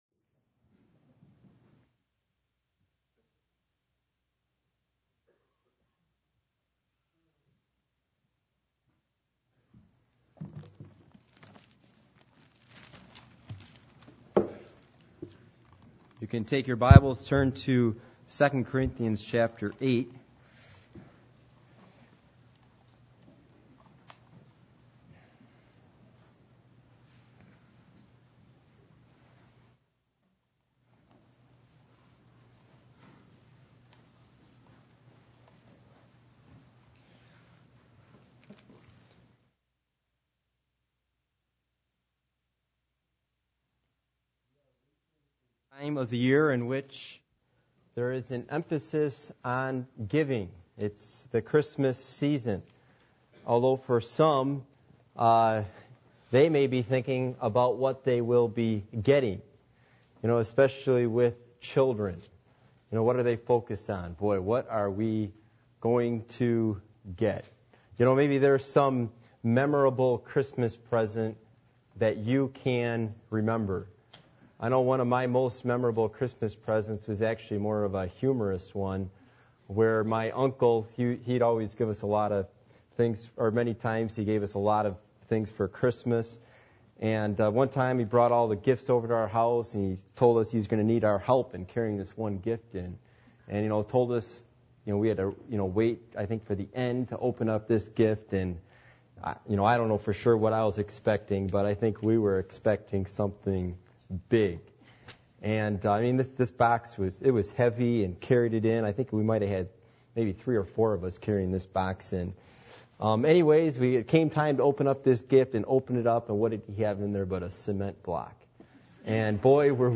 2 Corinthians 8:7-11 Service Type: Sunday Evening %todo_render% « The Day Of Atonement The New Testament Church